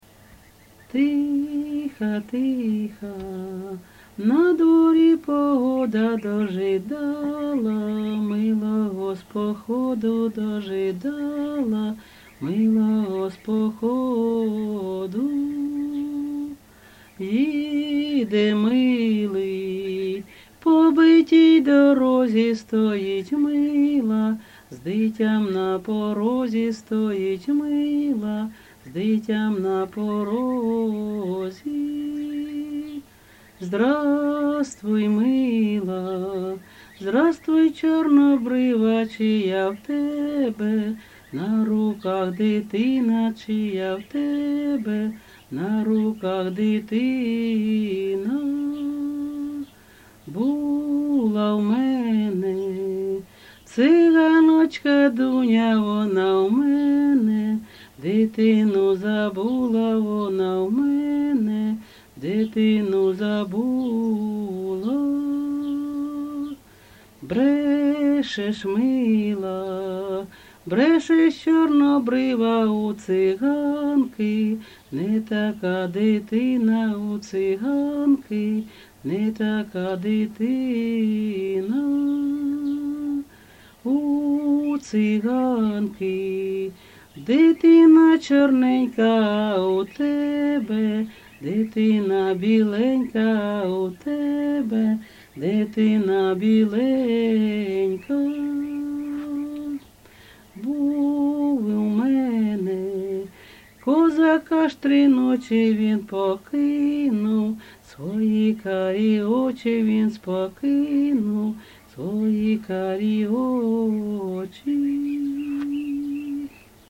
ЖанрПісні з особистого та родинного життя, Балади
Місце записус. Серебрянка, Артемівський (Бахмутський) район, Донецька обл., Україна, Слобожанщина